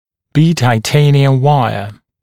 [‘biːtə-taɪ’teɪnɪəm ‘waɪə][ˌби:тэ-тай’тэйниэм ‘уайэ]бета-титановая дуга